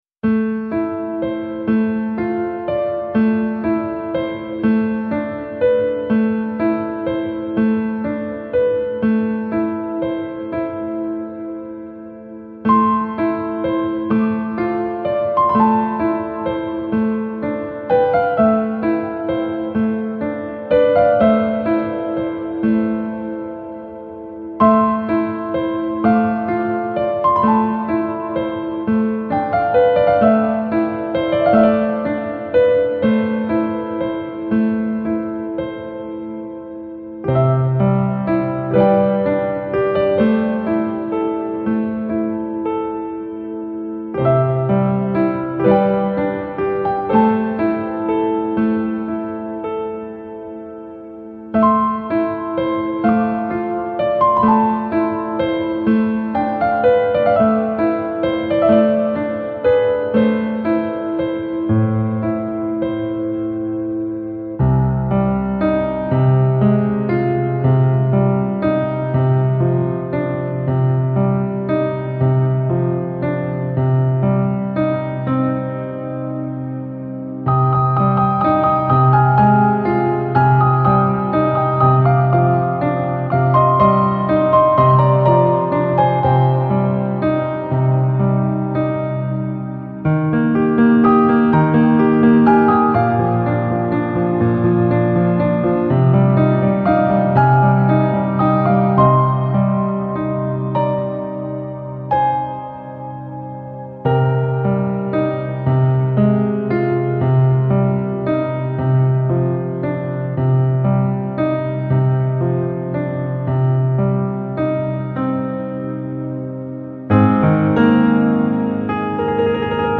Жанр: New Age, Piano